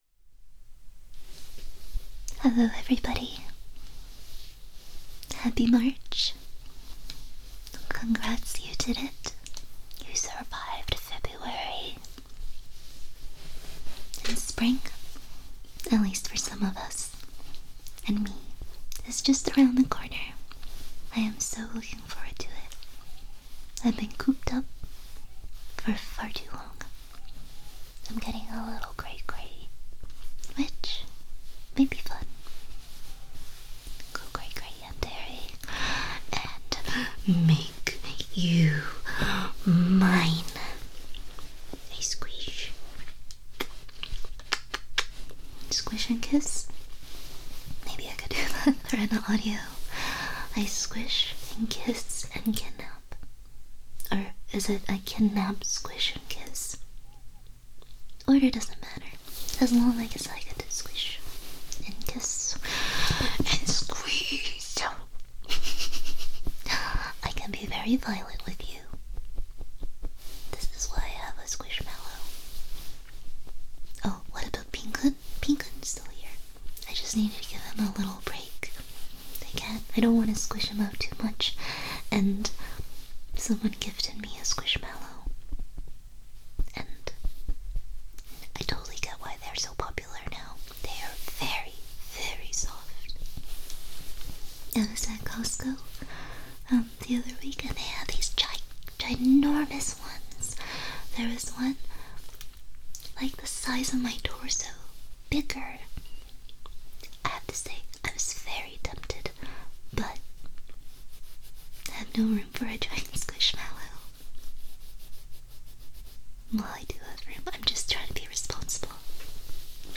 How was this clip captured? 📌 Note: Embedded audio is off-topic and was something I recorded earlier today.